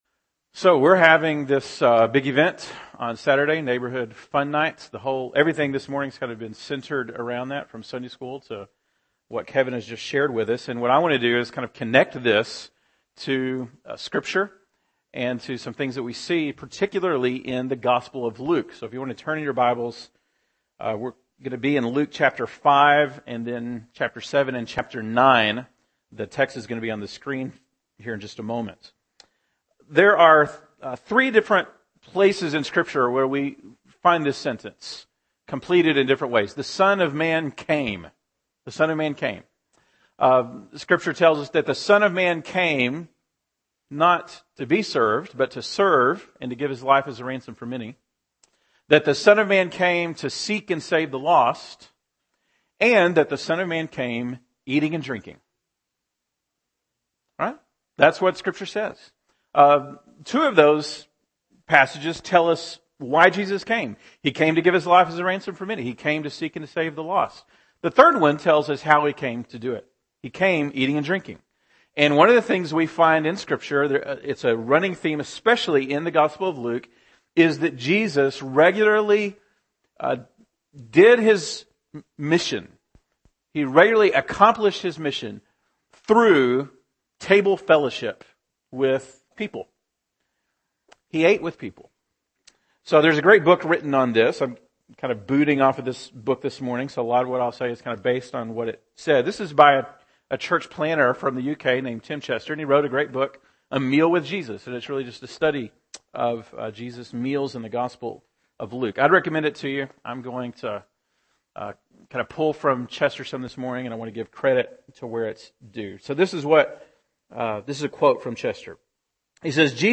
October 6, 2013 (Sunday Morning)